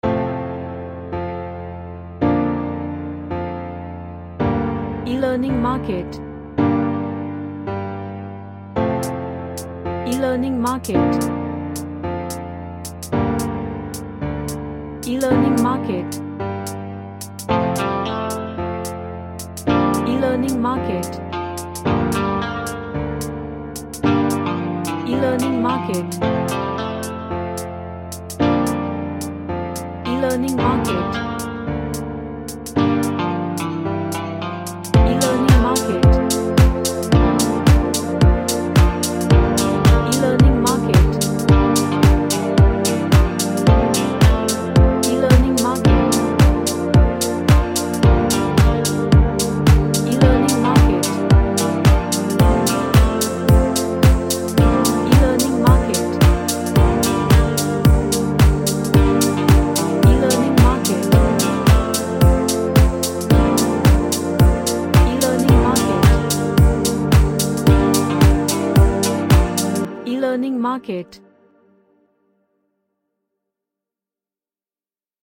A vibey melodical indie track.
Sad / Nostalgic